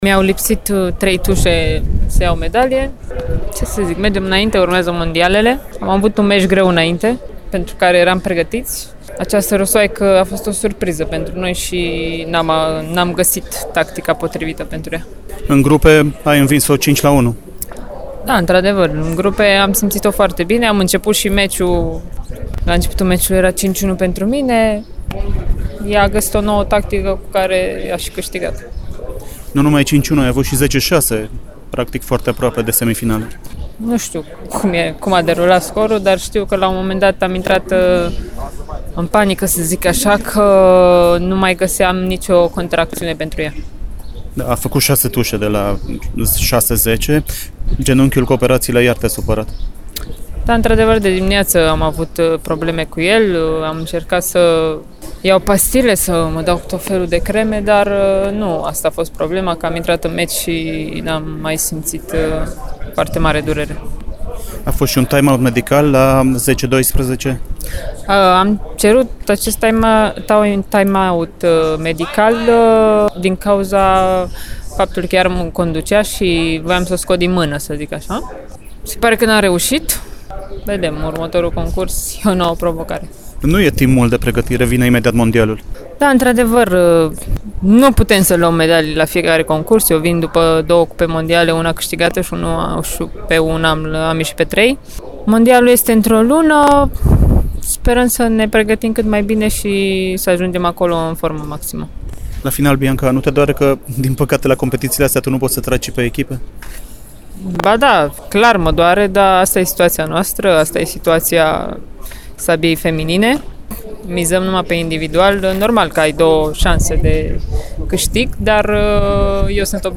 Interviul pe care Bianca Pascu l-a oferit postului Radio România